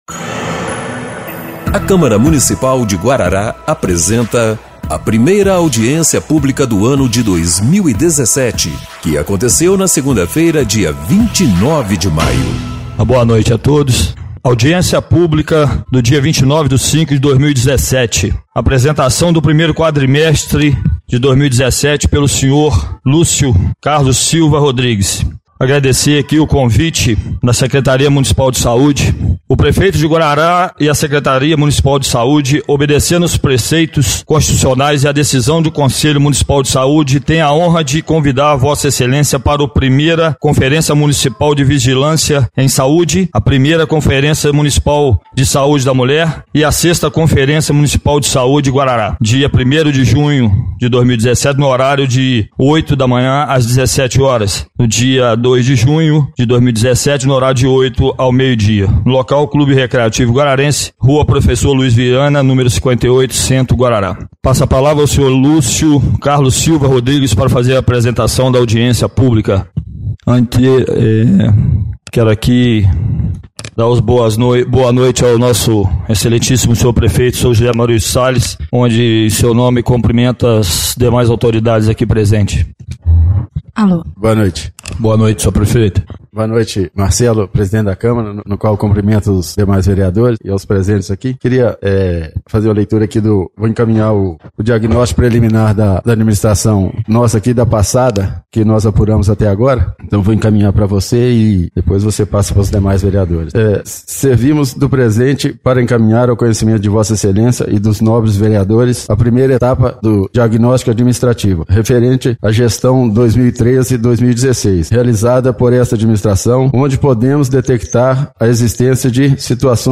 1ª Audiência Pública de 29/05/2017